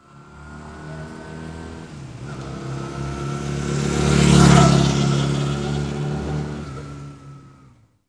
Index of /90_sSampleCDs/AKAI S6000 CD-ROM - Volume 6/Transportation/MOTORCYCLE
400-PASS.WAV